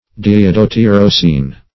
diiodotyrosine \di*i`o*do*tyr"o*sine\
diiodotyrosine.mp3